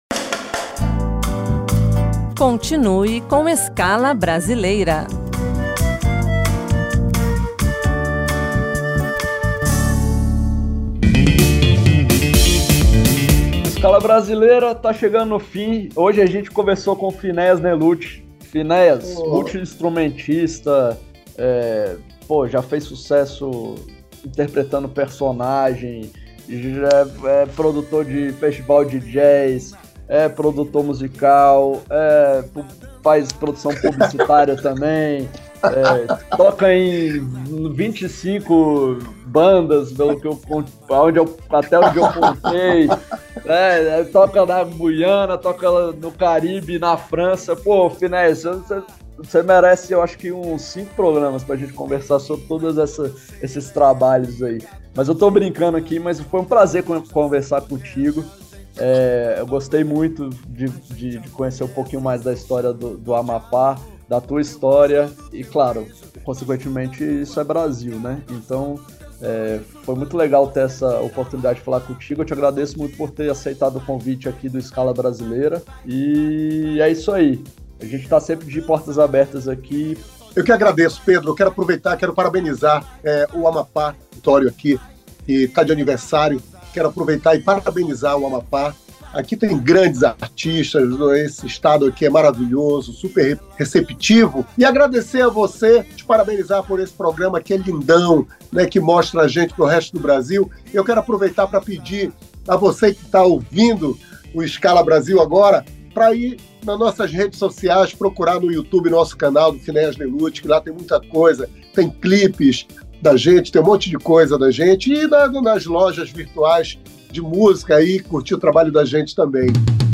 entrevista
AGRADECIMENTO E SEQUÊNCIA FINAL DE MÚSICAS DO ARTISTA